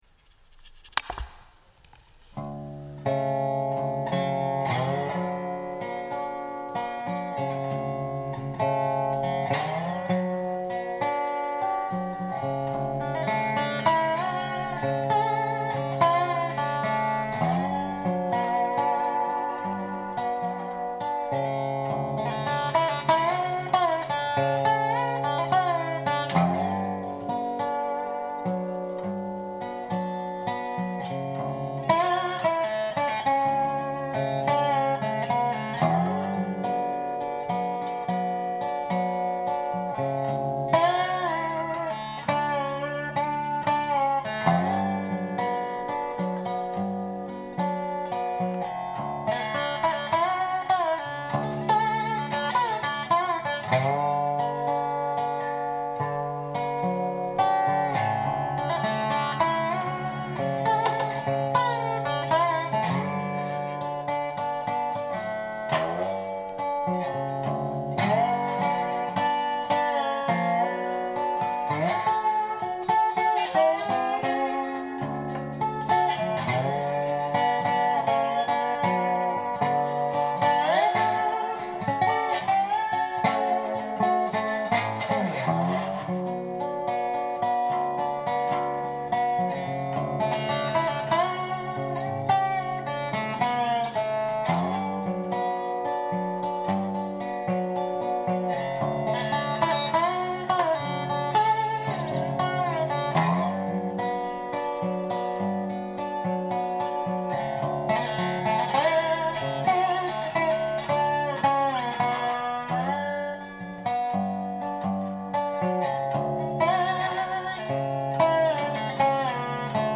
Unnamed slide piece